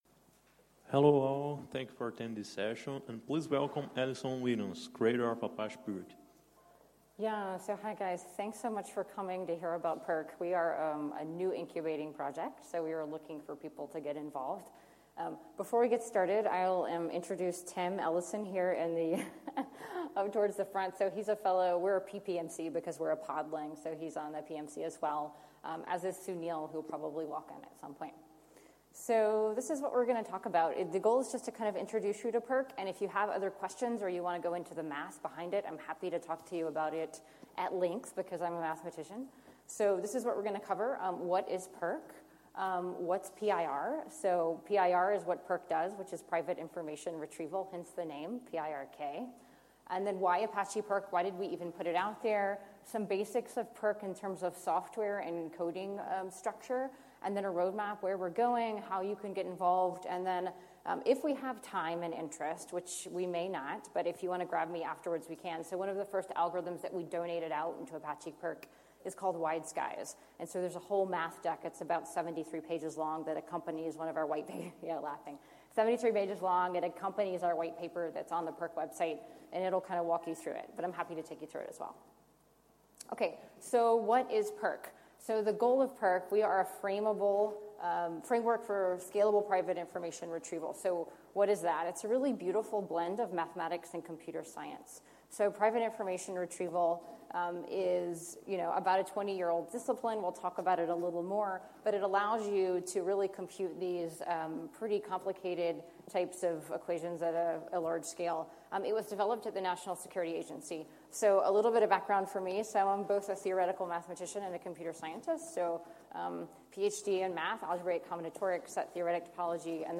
Apache Big Data Seville 2016
This talk will introduce Apache Pirk – a new incubating Apache project designed to provide a framework for scalable, distributed PIR. We will discuss the motivation for Apache Pirk, its distributed implementations in platforms such as Spark and Storm, itäó»s current algorithms, the power of homomorphic encryption, and take a look at the path forward.